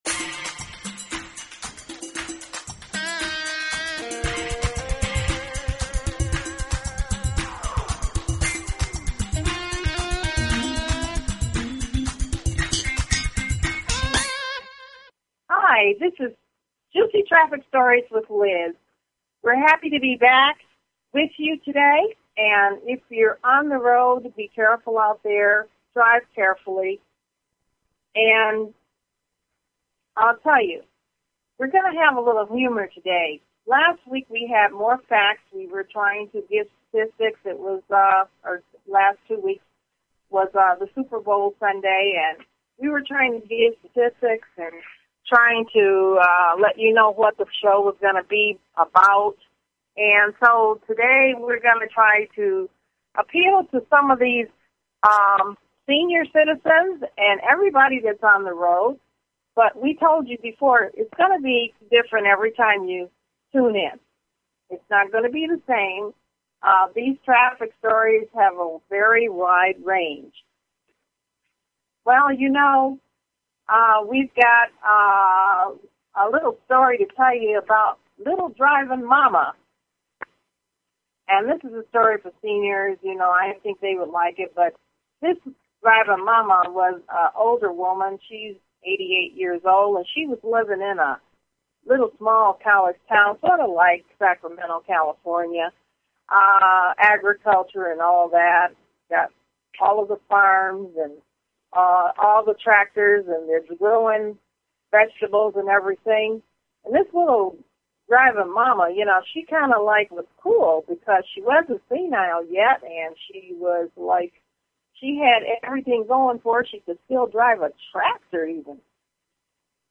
Talk Show Episode, Audio Podcast, Juicy_Traffic_Stories and Courtesy of BBS Radio on , show guests , about , categorized as